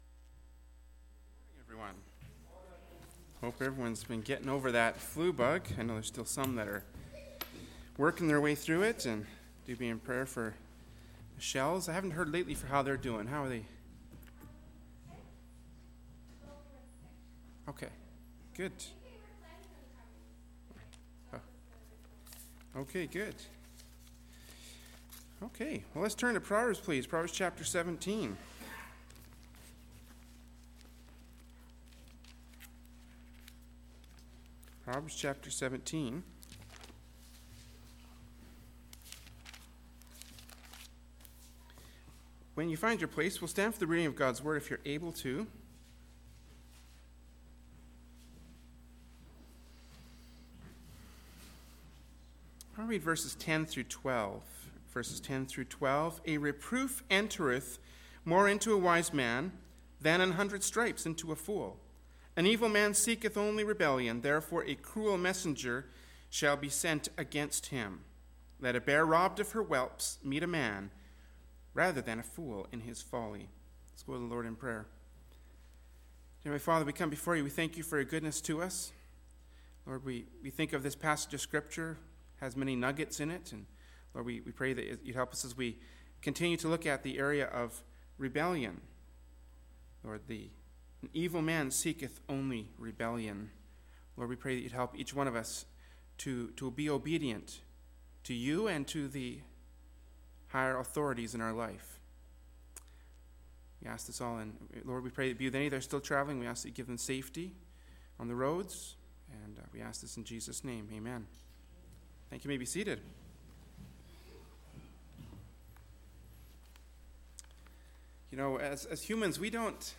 “Proverbs 17:10-12” from Sunday School Service by Berean Baptist Church.